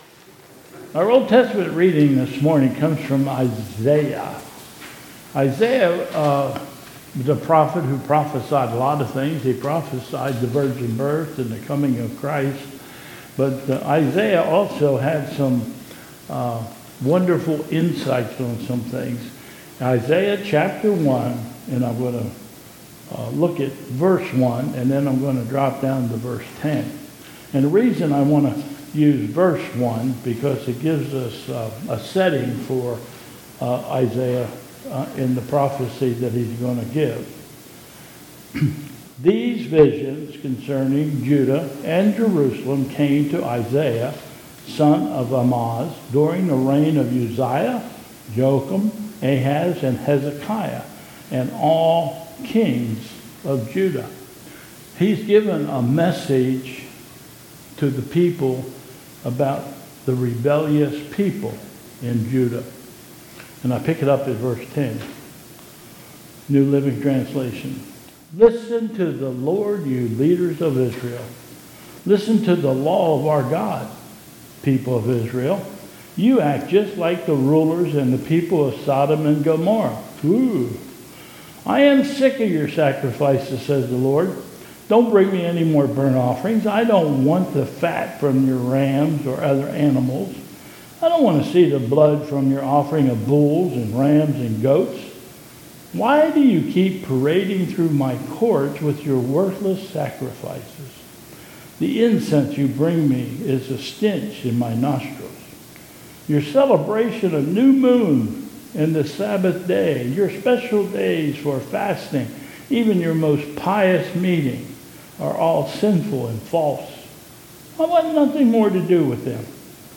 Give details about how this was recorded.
2022 Bethel Covid Time Service